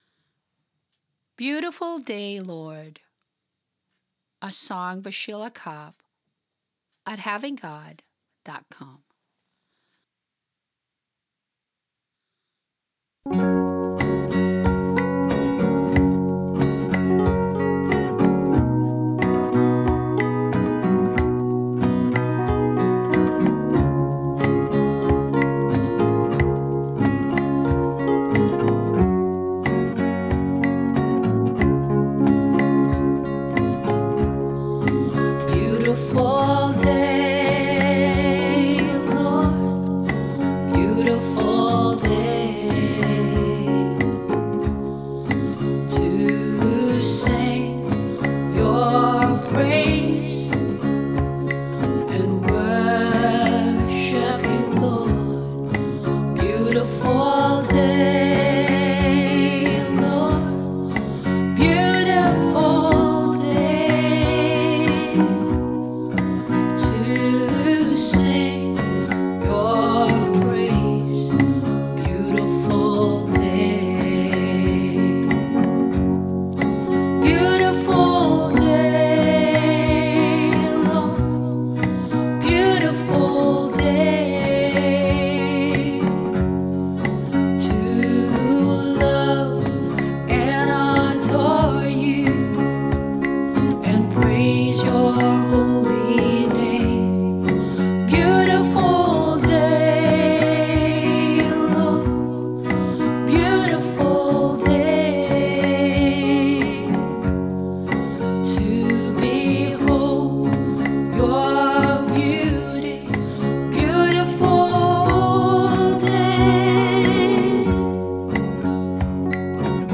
Vocals, guitar, bass and drum machine
Keyboard and organ